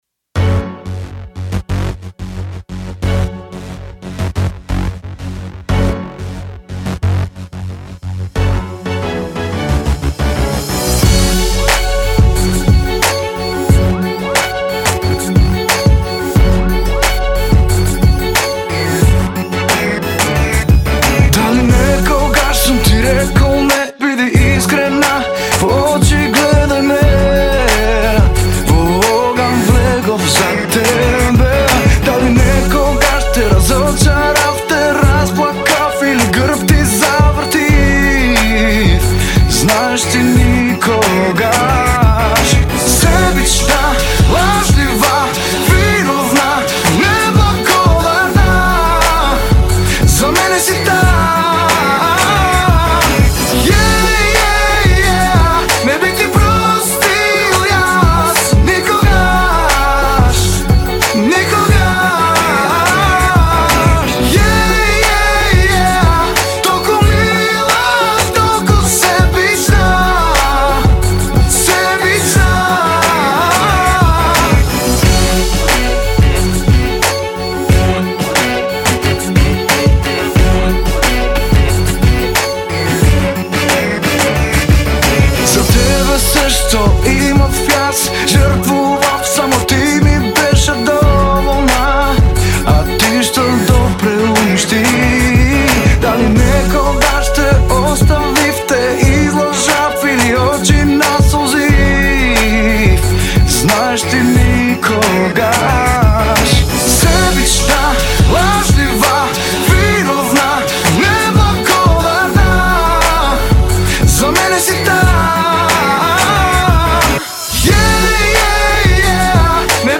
придружните вокали